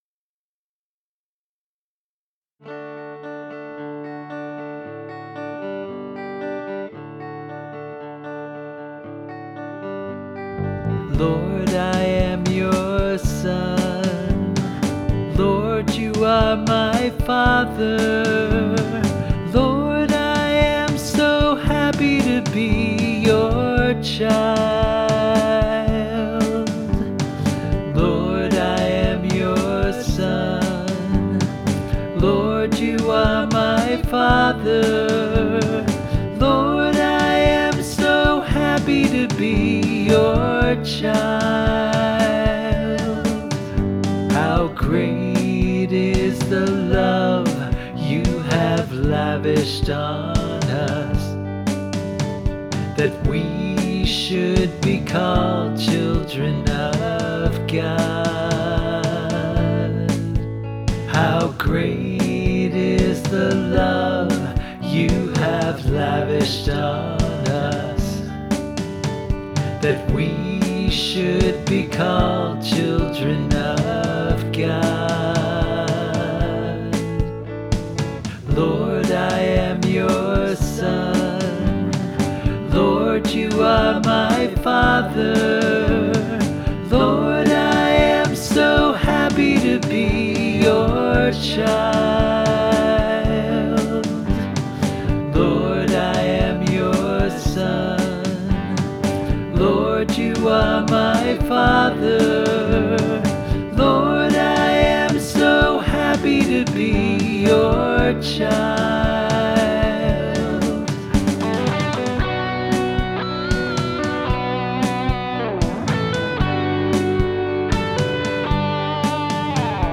Vocals, Guitars, Bass
Lead Guitar
Drums